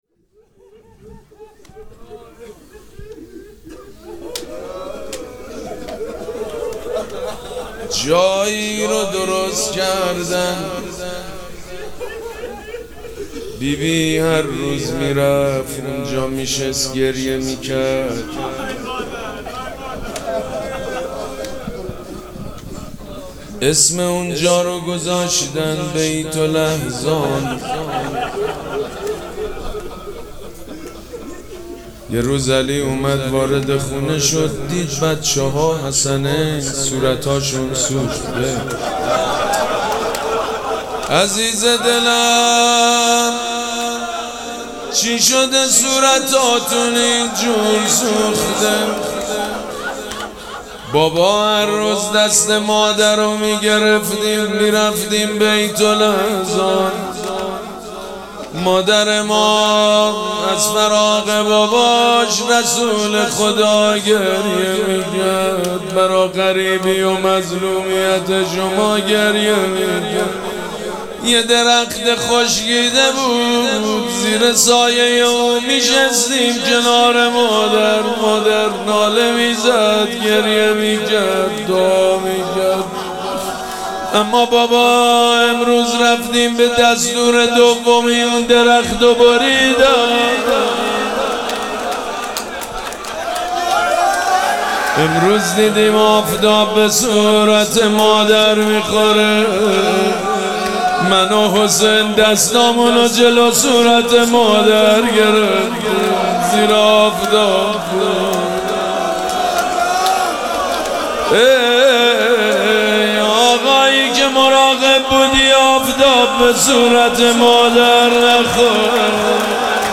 روضه حضرت زهرا(س) – محتوانشر